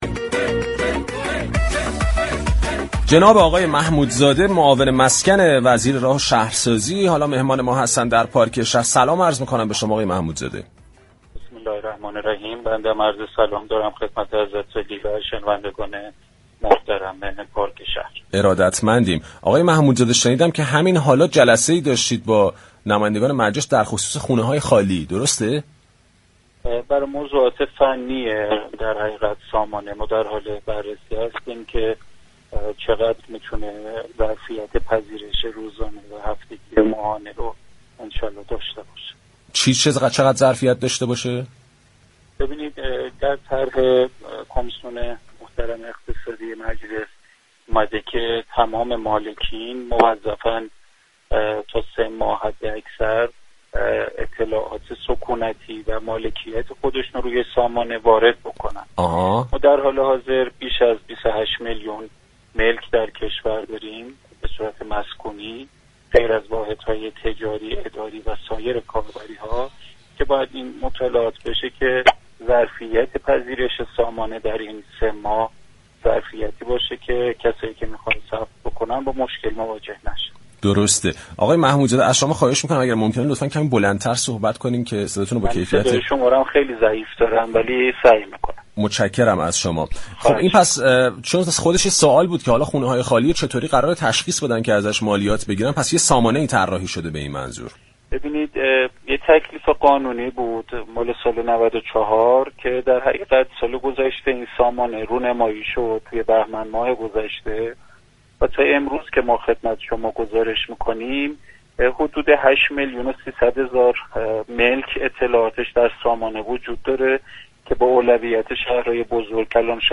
محمودزاده، معاون مسكن وزیر راه و شهرسازی در گفتگو با پارك شهر گفت: 2 میلیون و 220 هزار نفر در طرح كمك ودیعه اجاره مسكن ثبت نام كرده اند و تا 20 مرداد لیست اسامی افراد واجد شرایط به بانك ها برای پرداخت كمك ودیعه اجاره ارسال خواهد شد.